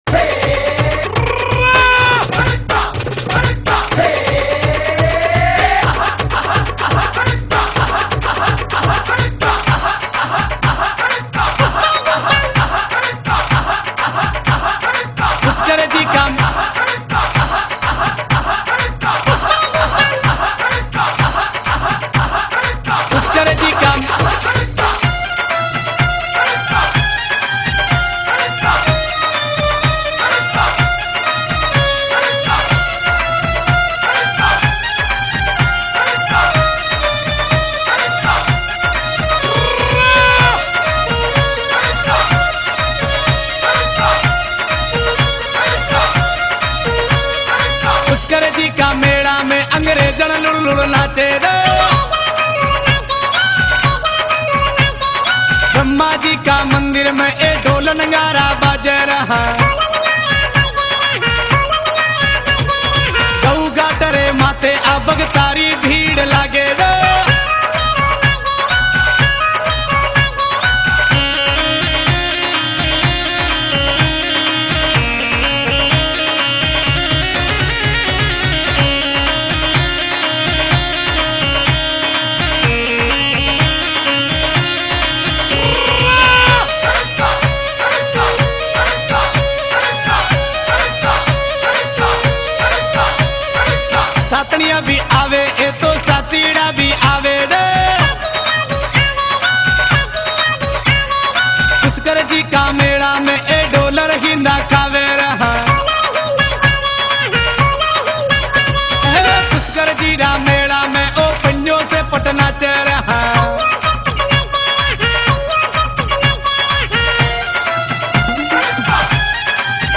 rajasthani beats